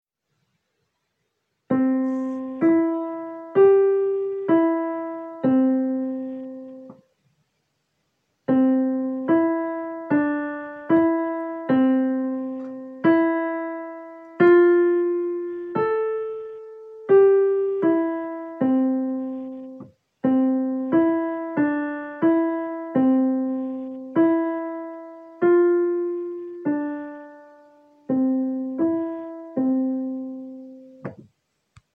3_kl-diktant_do_mazhor.mp3